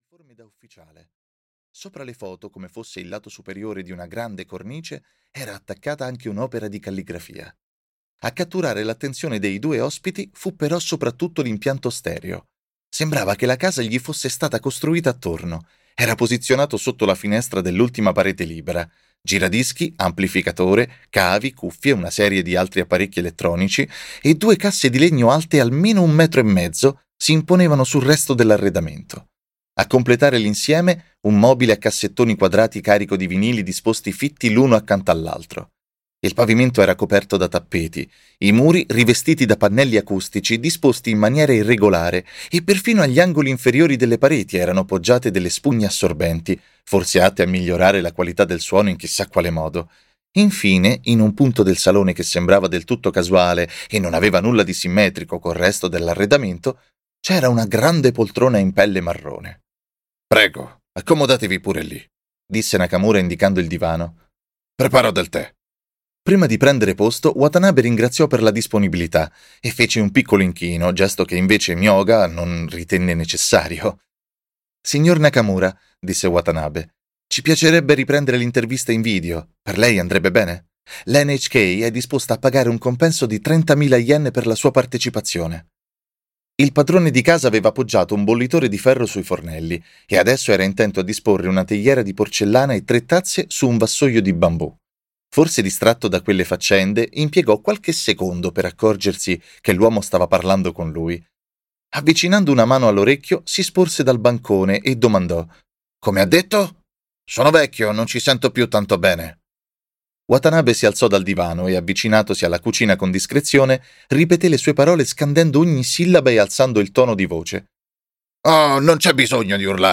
Audiolibro digitale